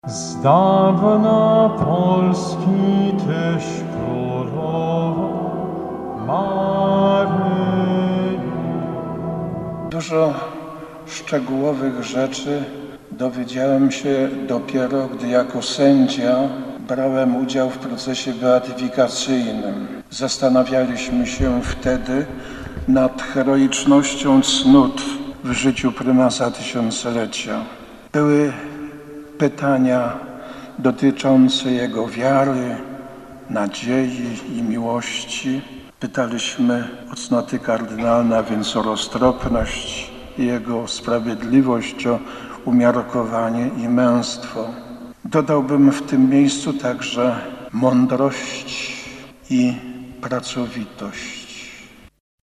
Warszawscy wierni modlą się o kolejnego świętego. Jak każdego dwudziestego ósmego dnia miesiąca w archikatedrze warszawskiej została odprawiona Msza św. w intencji beatyfikacji kardynała Stefana Wyszyńskiego.